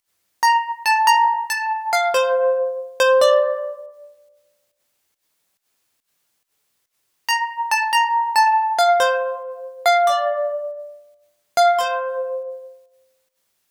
VTS1 Devastating Truth Kit 140BPM Soft Pluck Main DRY.wav